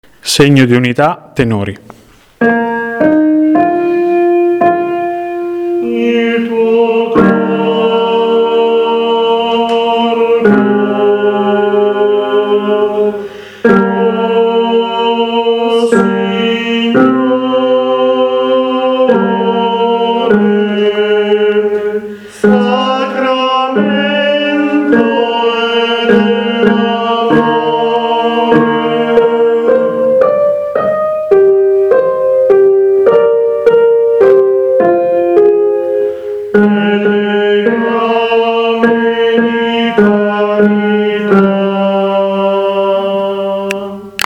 Parti Cantate
In questa sezione sono raccolte le parti cantate, delle diverse sezioni, in riferimento ad alcuni dei brani del Repertorio Condiviso.